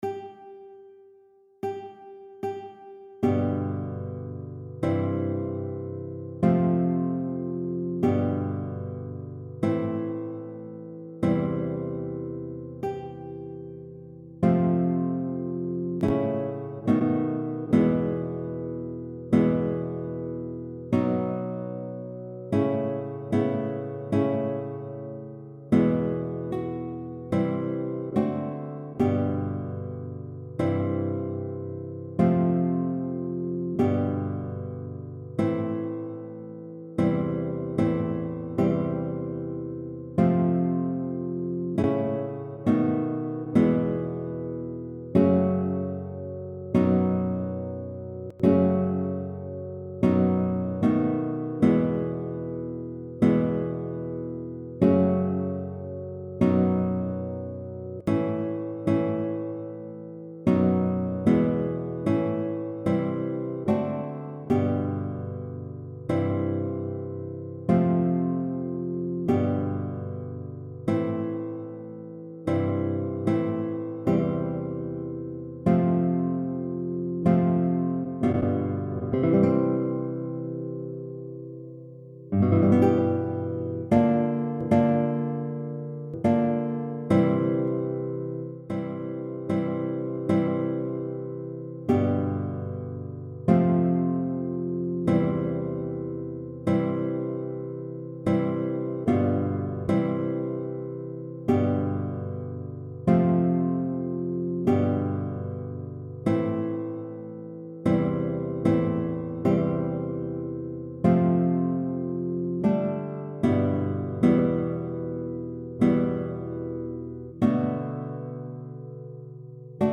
Computer Guitar only: